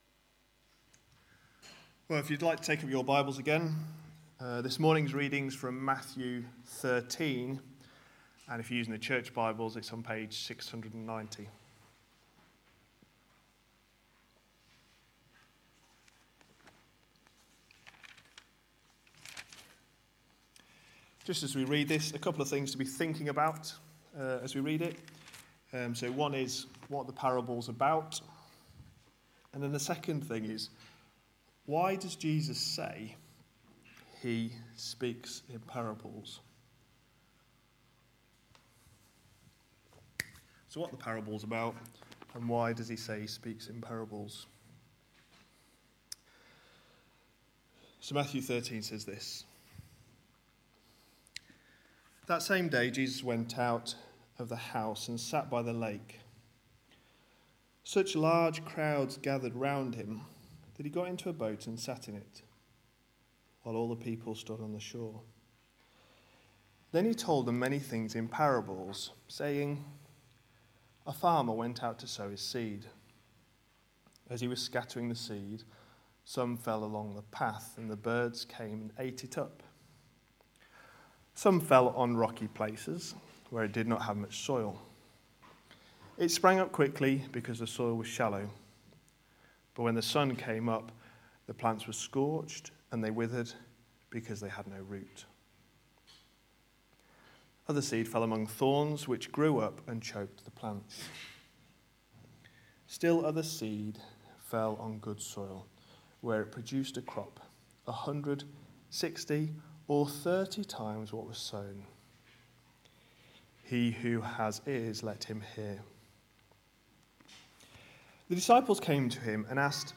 A sermon preached on 22nd November, 2015, as part of our Matthew series.